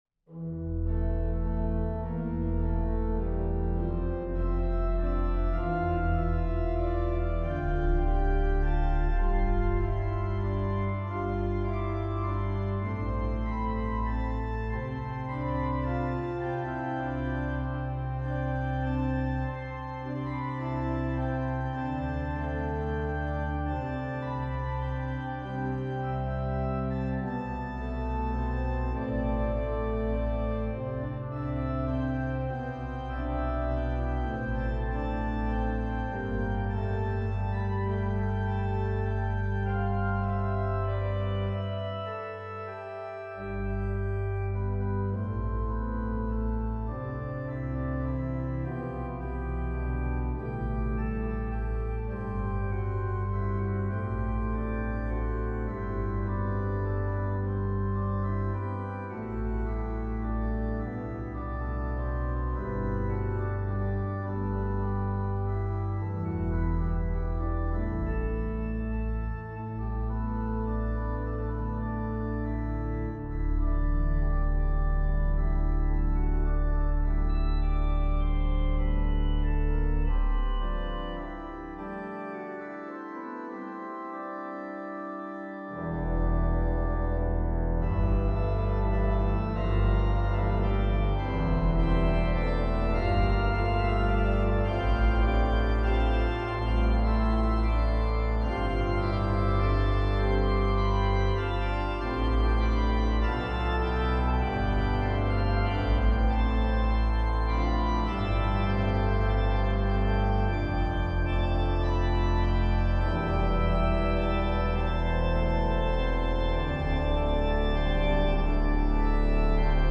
Voicing: Org 3-staff